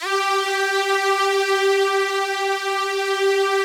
SPCSTR. G4-L.wav